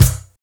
CC - Sealed Kick.wav